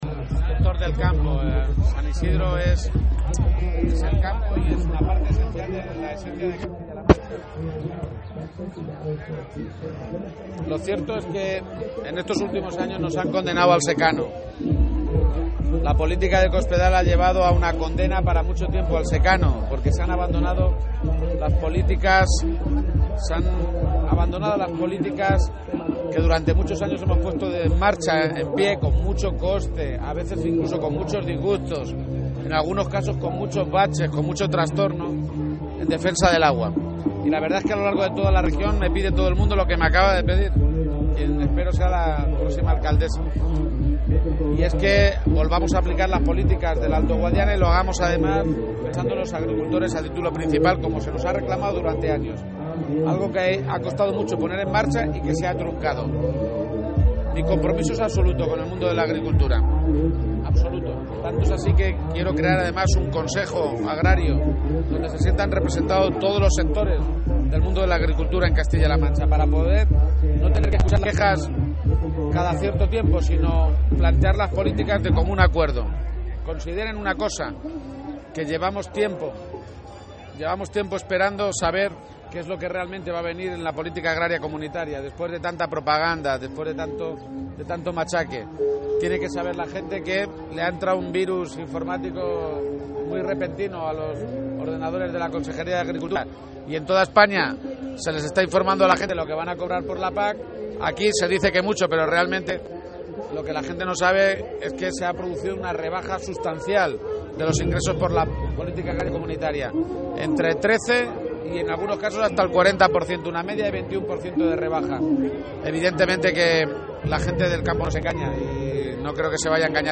García-Page que realizó estas declaraciones en la Romería de San Isidro de Campo de Criptana, por otra parte, criticó duramente que el de Cospedal sea el único gobierno de España que todavía no haya hecho públicos los datos sobre cuánto se va a percibir en nuestra comunidad autónoma de los fondos de la Política Agraria Comunitaria (PAC).